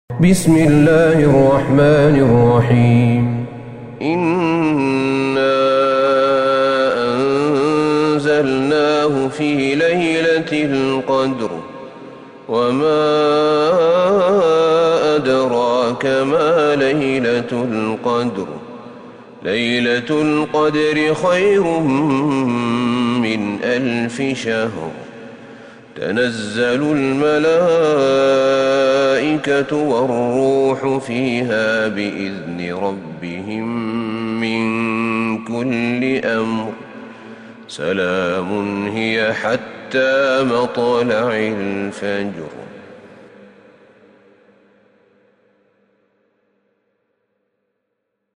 سورة القدر Surat Al-Qadr > مصحف الشيخ أحمد بن طالب بن حميد من الحرم النبوي > المصحف - تلاوات الحرمين